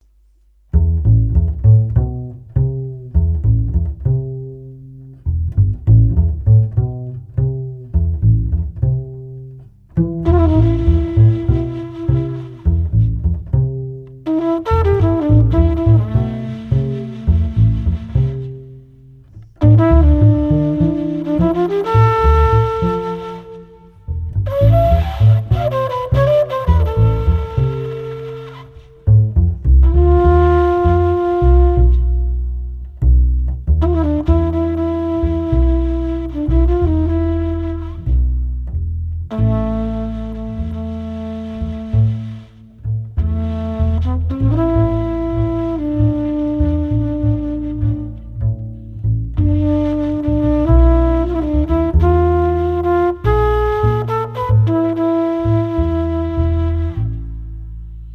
• Jazz
• Latin